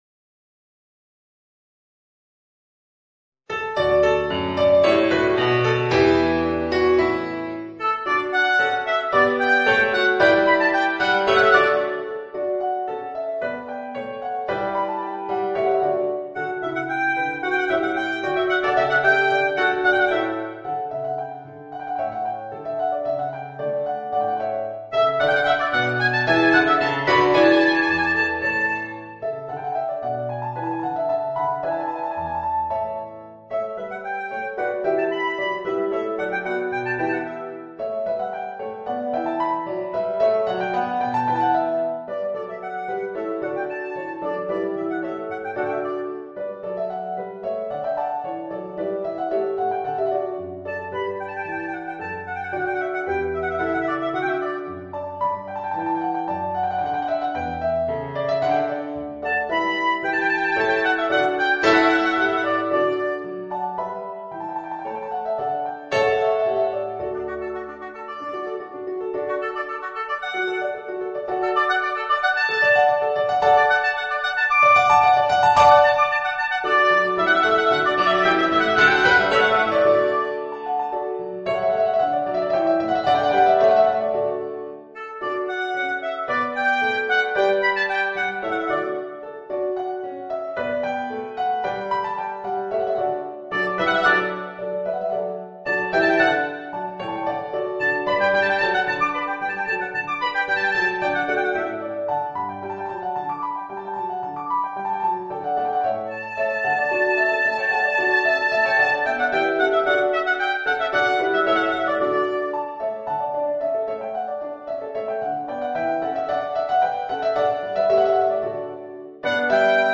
Voicing: Oboe and Organ